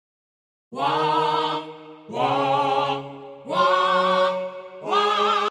描述：男女合唱团唱一首歌，用不同的调子。
Tag: 88 bpm Orchestral Loops Vocal Loops 939.80 KB wav Key : Unknown